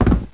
The original sound reduced by only 6dB compared to the much more aggressively softened previously committed version, which was inaudible in some setups. 2023-11-17 21:22:57 -08:00 7.7 KiB Raw Permalink History